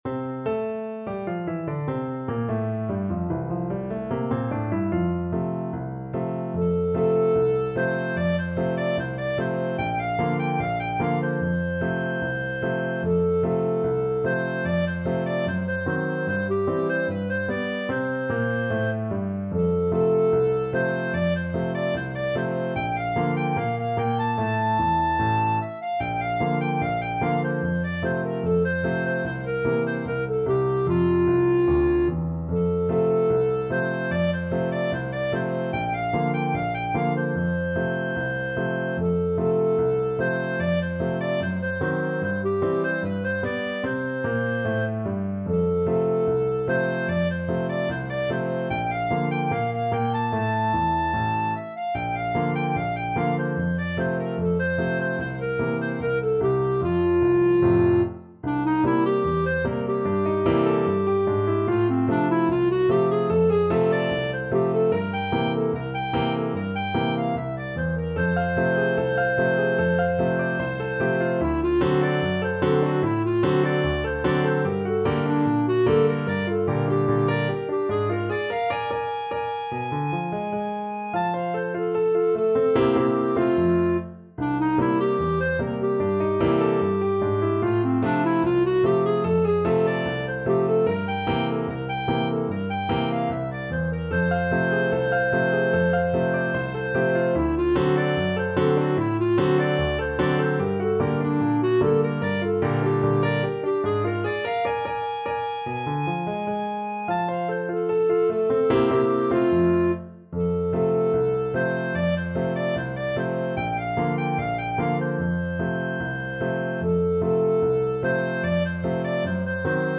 2/4 (View more 2/4 Music)
Not Fast = 74
Jazz (View more Jazz Clarinet Music)